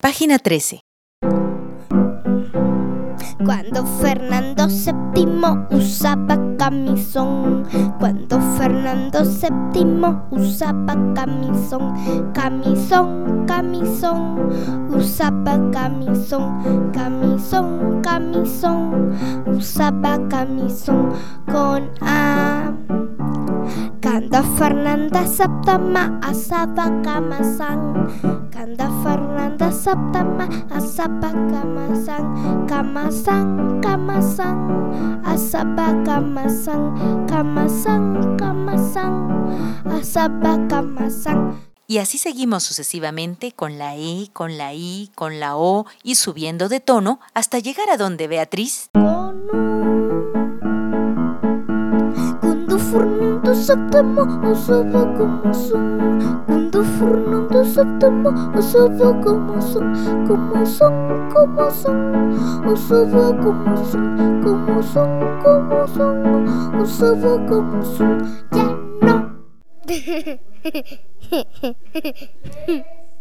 vocalización